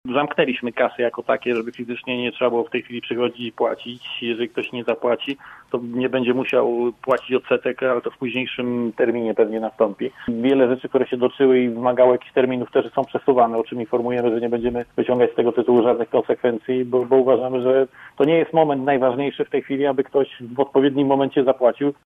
Prezydent Zielonej Góry Janusz Kubicki w Rozmowie Punkt 9 wskazywał, że miejskimi autobusami jeździ mniej pasażerów, stąd planowana redukcja kursów.